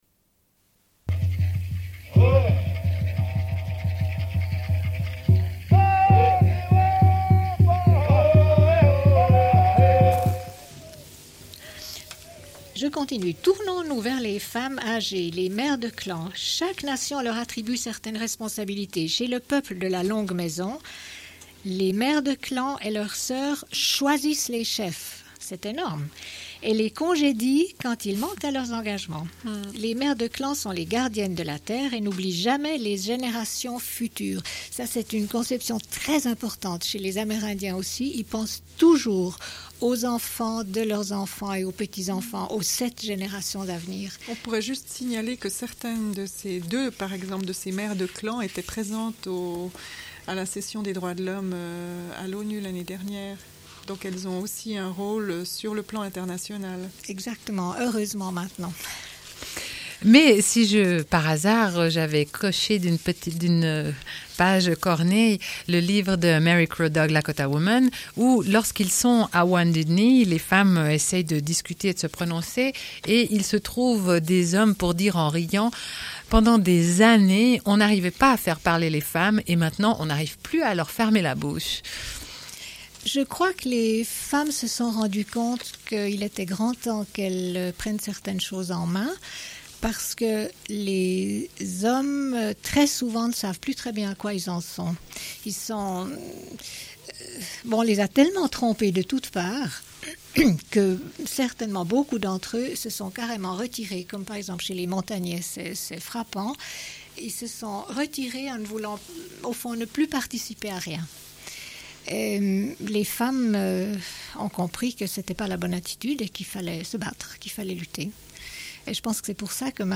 Une cassette audio, face A31:24